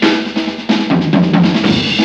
JAZZ BREAK16.wav